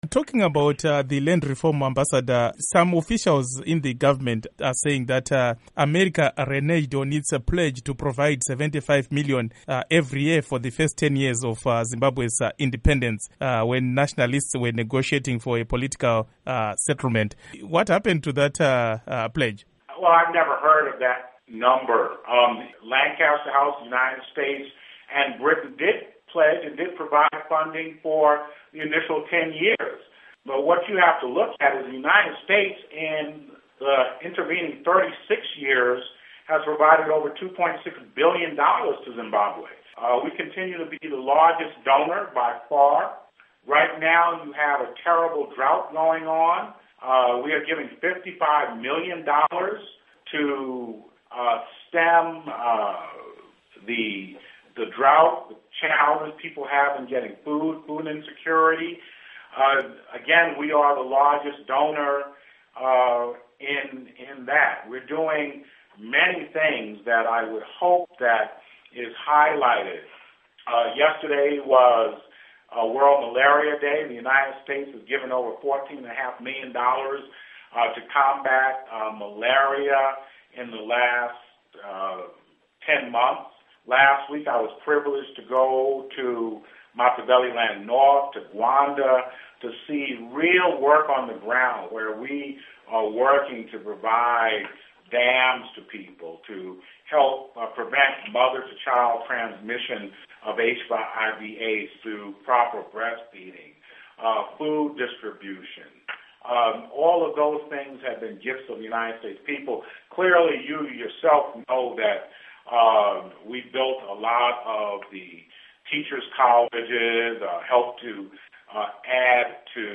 Interview With Ambassador Harry Thomas Jnr. on Zimbabwe Land Reform Program